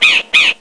crowcaw.mp3